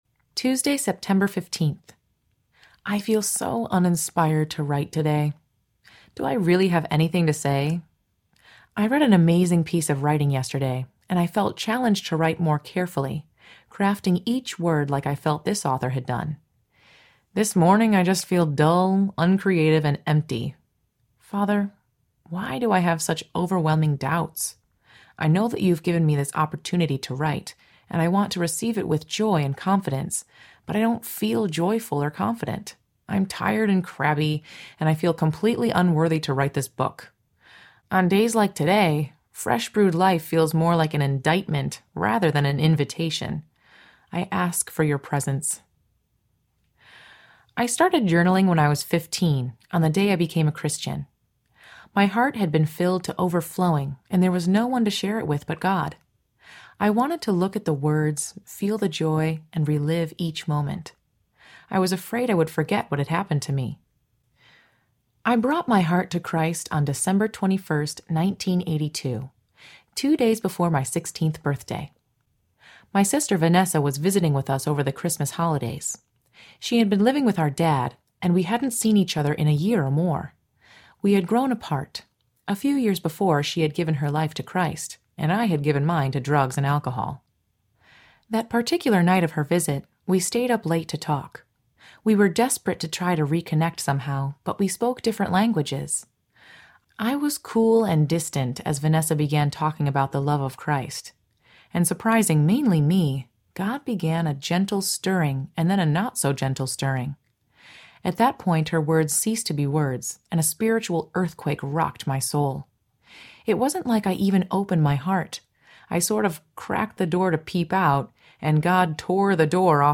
Fresh-Brewed Life Audiobook
Narrator